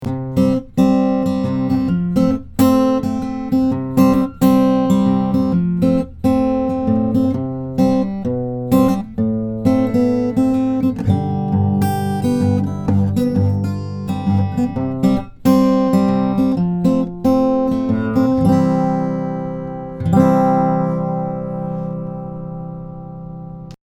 This Composite Acoustics X Performer Carbon Burst looks great, plays very easily for an acoustic, and sounds deep and loud.
Acoustic guitar innovation at its finest
• One Piece Carbon Fiber Body and Neck (all one piece)
• L.R. Baggs I-Mix Pickup